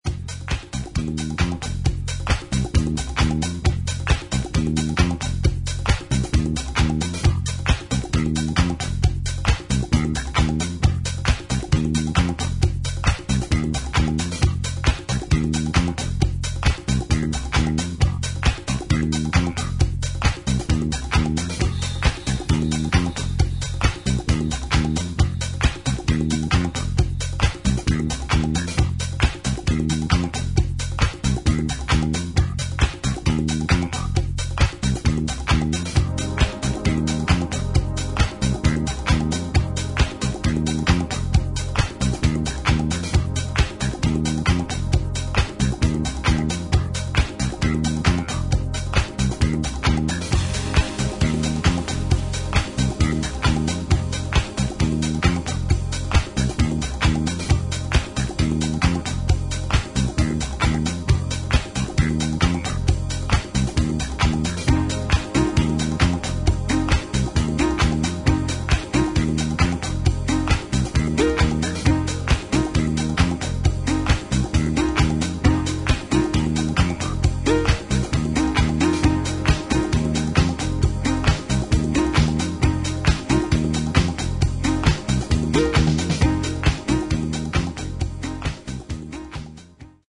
生のリズムサンプリングで組まれたグルーヴにエレクトロニックサウンドのディスコ風フレーズが絶妙なバランスで絡む